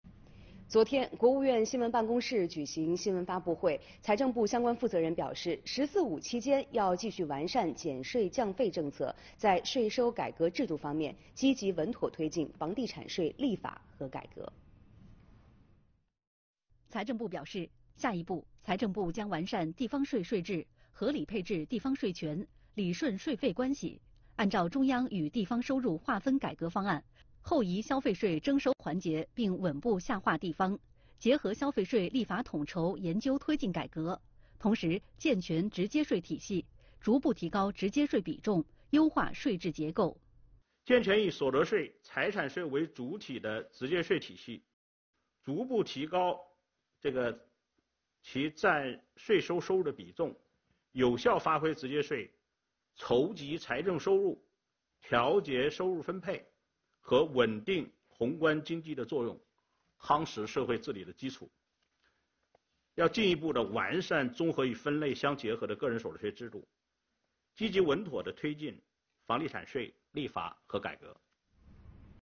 4月7日，国务院新闻办公室举行新闻发布会。
财政部税政司司长王建凡在发布会上表示，健全地方税、直接税体系是完善我国现代税收制度的重要内容。“